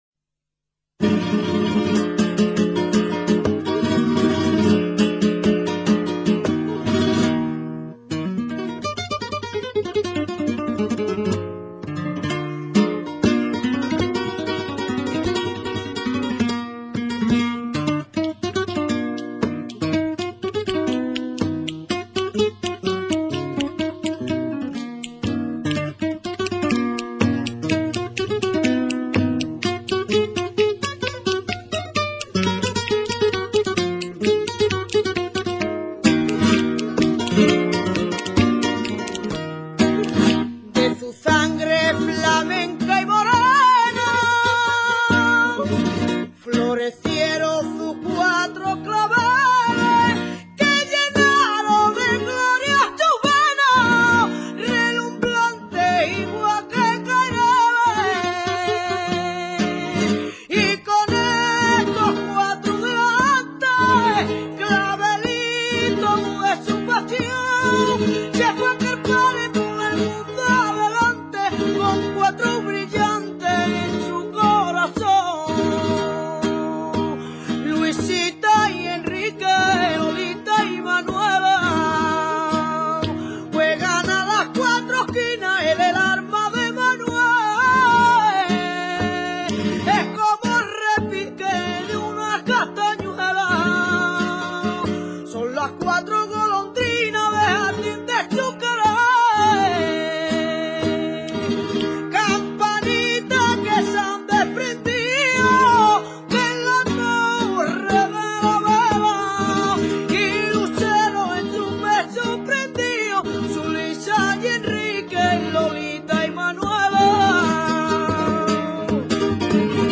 Cante aflamencado de origen gallego, con copla de cuatro versos octos�labos que riman segundo y cuarto. Es cadencioso y melanc�lico con claras influencias de algunas formas gaditanas.
farruca.mp3